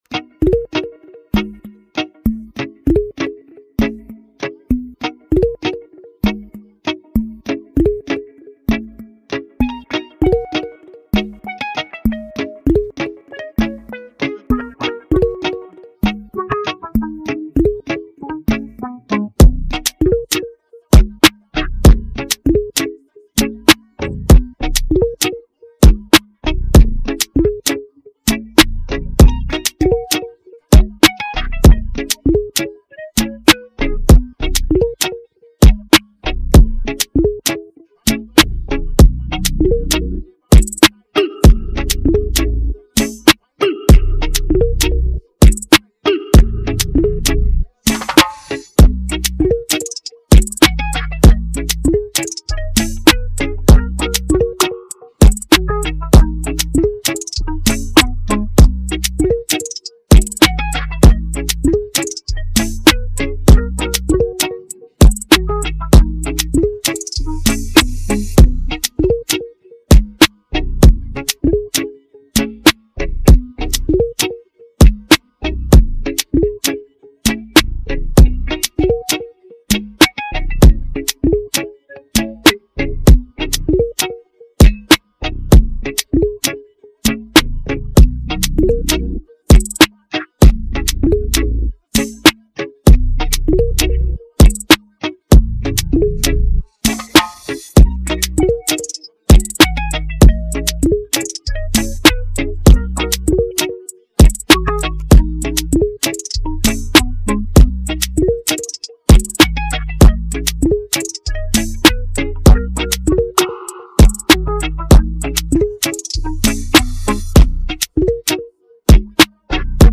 Afro dancehall